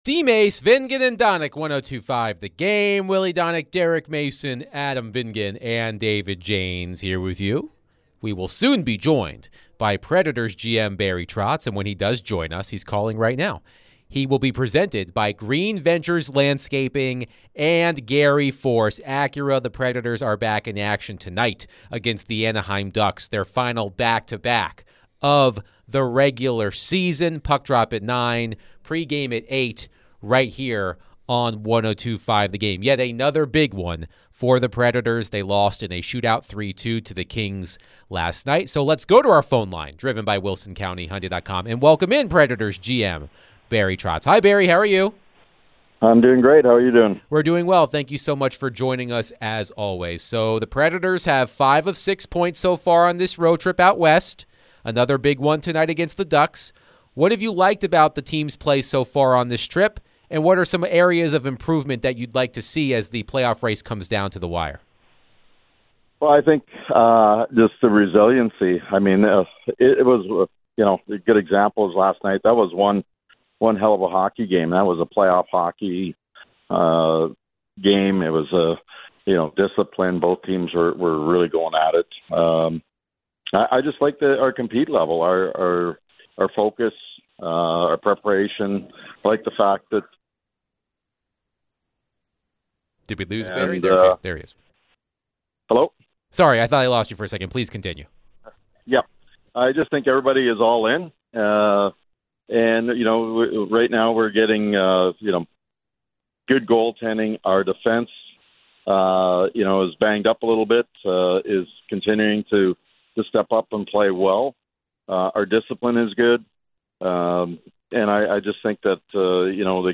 Nashville Predators GM Barry Trotz joined DVD for his weekly chat.